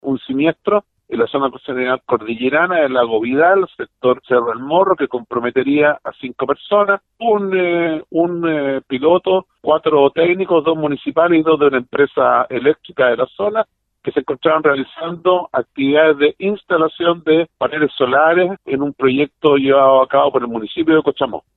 Alejandro Vergués, director regional de la Onemi, aportó los primeros antecedentes del hecho. En ese sentido, indicó que los operarios estaban instalando paneles solares en un proyecto liderado por el municipio.